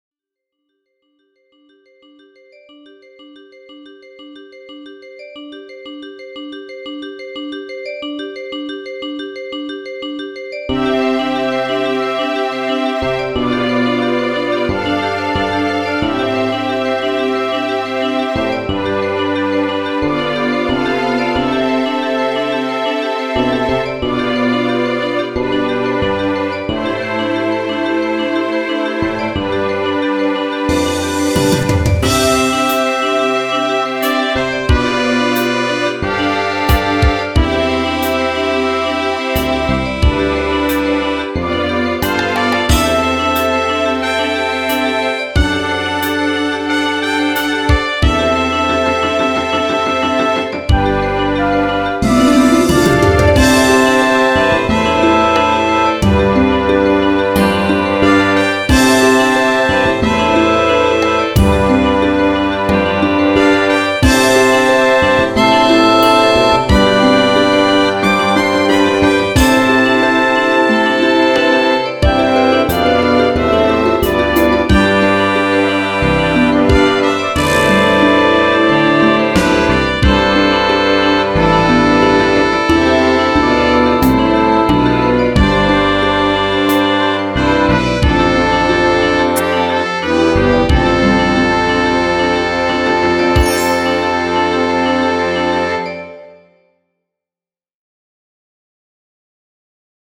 ～～壮大な曲～～